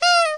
Fnaf Boop